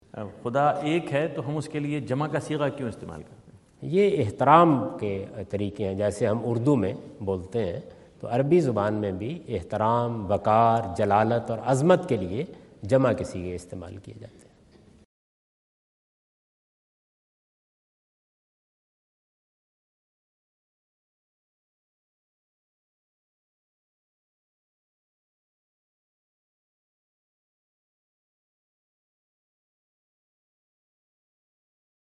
Javed Ahmad Ghamidi answer the question about "Using Plural Pronoun for God" asked at North Brunswick High School, New Jersey on September 29,2017.